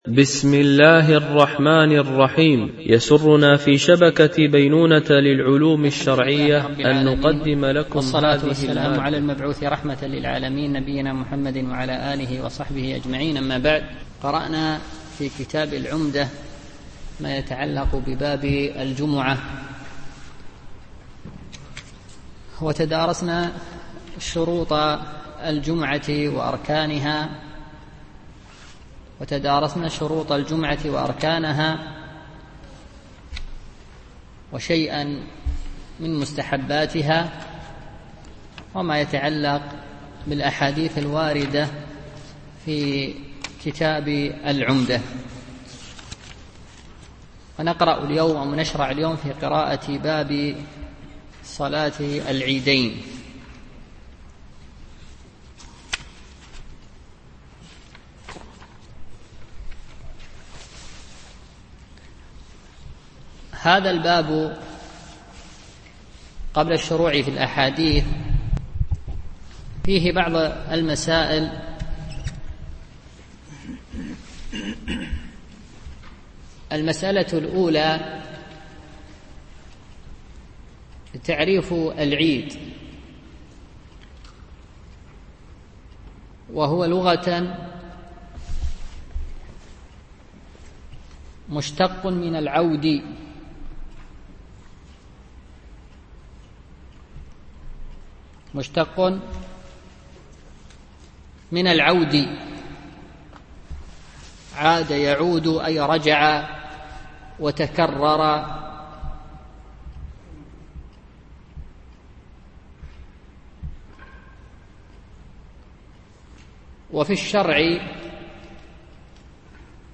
شرح عمدة الأحكام ـ الدرس 38 (الحديث 145 - 149)